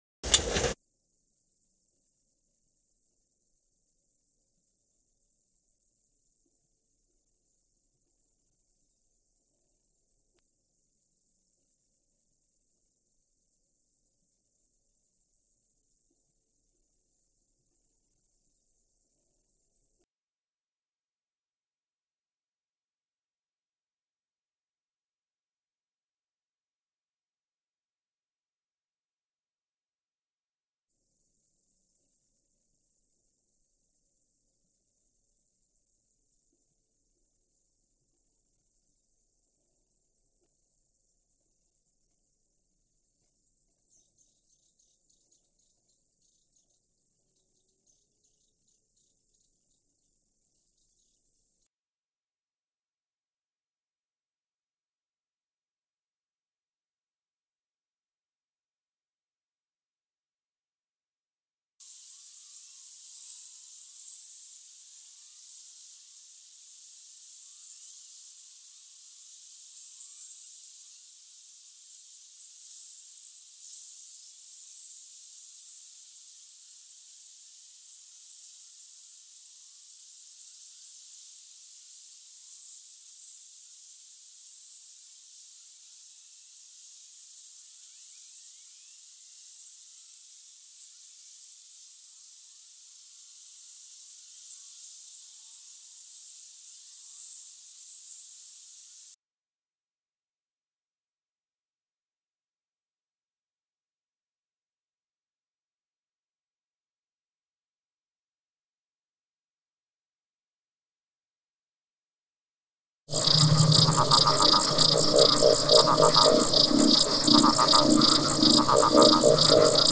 experimental music and audio art